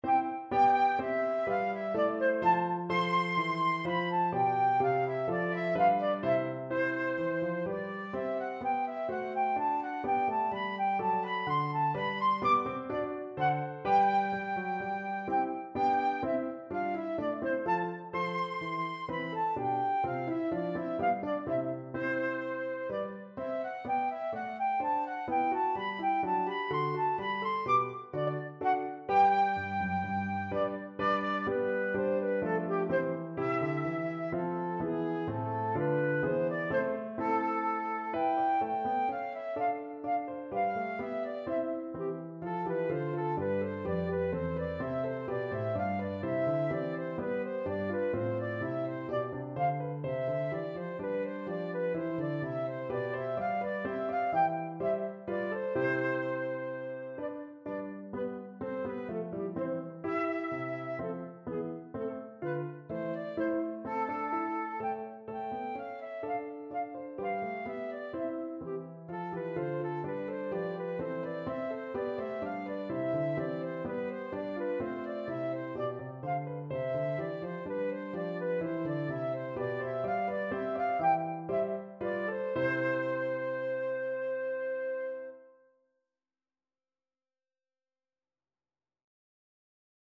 Flute
4/4 (View more 4/4 Music)
C major (Sounding Pitch) (View more C major Music for Flute )
Allegretto = 126
Classical (View more Classical Flute Music)